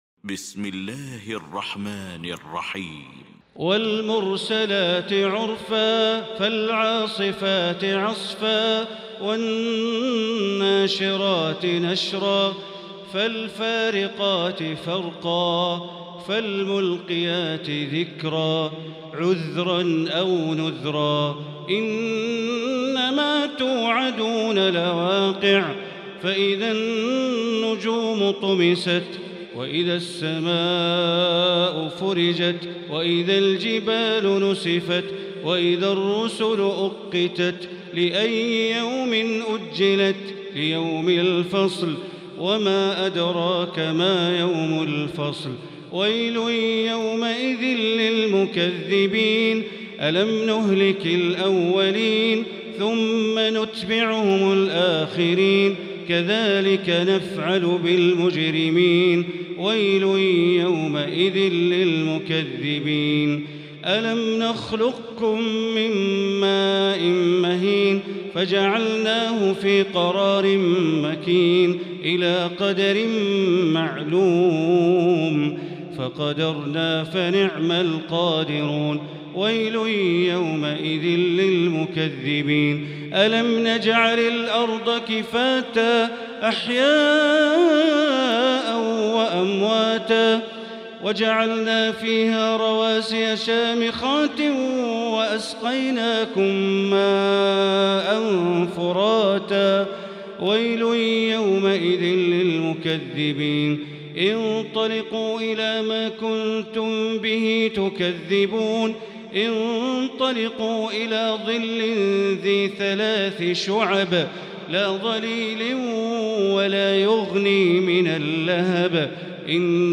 المكان: المسجد الحرام الشيخ: معالي الشيخ أ.د. بندر بليلة معالي الشيخ أ.د. بندر بليلة المرسلات